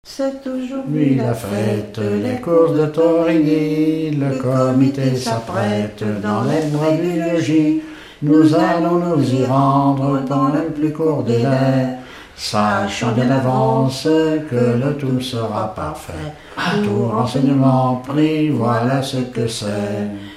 Elle provient de Thorigny.
Expression(s) choeur ;
Pièce musicale inédite ( chanson, musique,... )